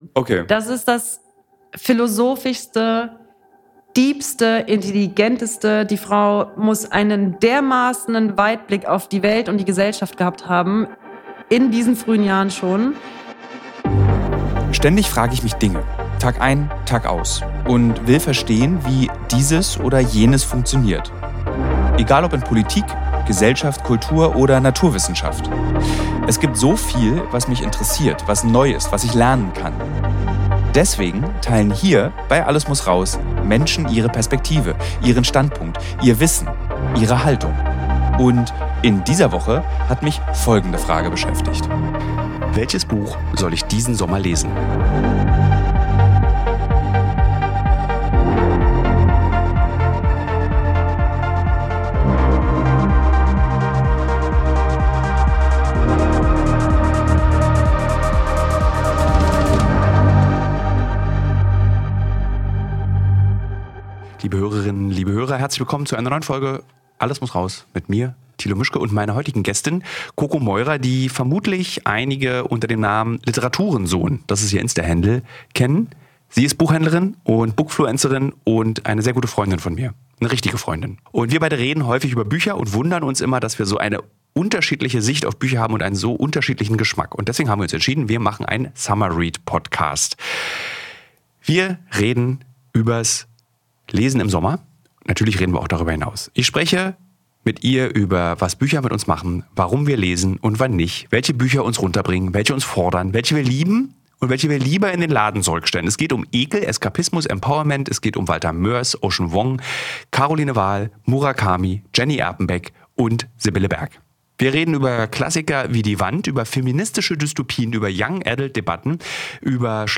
Es geht um gesellschaftliche und emotionale Wirkung von Literatur, Übersetzungen, die Rolle von Frauen im Buchhandel und den Reiz von Krimis und Romance als Urlaubslektüre. Ein Gespräch um die Kraft von Geschichten, um Literatur als Gegengewicht zu Schnelllebigkeit und Algorithmen – und darum, wie Bücher uns helfen können, die Welt (und uns selbst) besser zu verstehen.